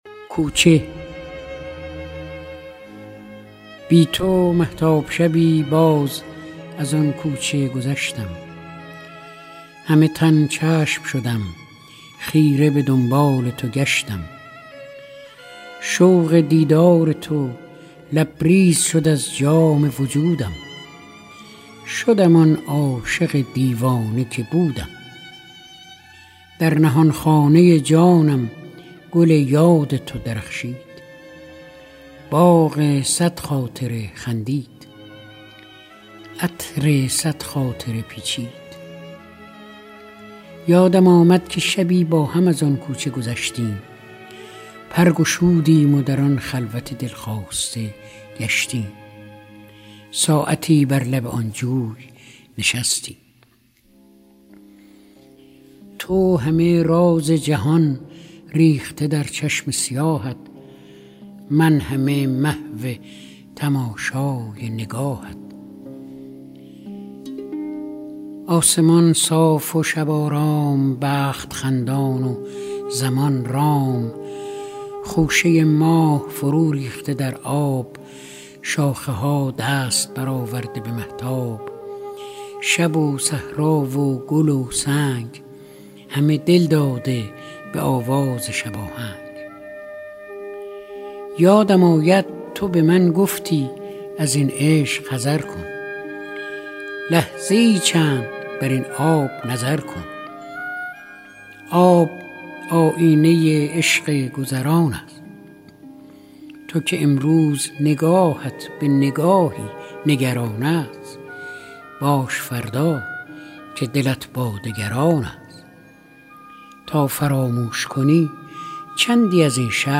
دکلمه شعر کوچه فریدون مشیری